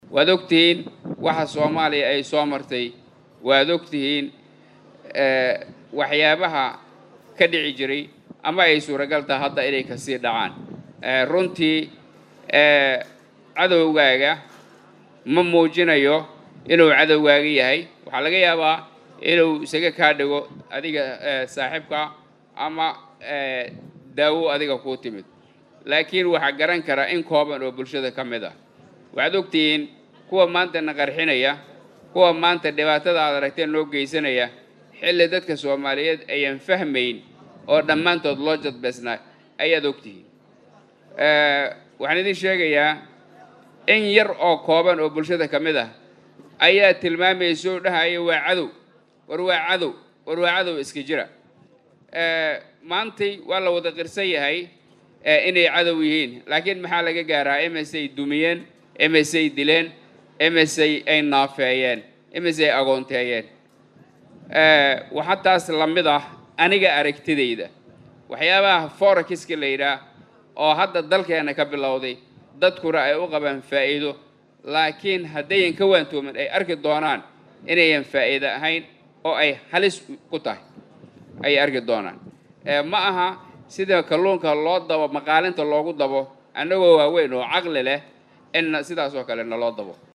Isaga oo ka hadlayay kulan Fanaaniin ka socotay Baydhaba uu uga qeybgalay Muqdisho ayuu sheegay in Ganacsiga Forex uu yahay mid lagu dhacayo dadka Soomaaliyeed.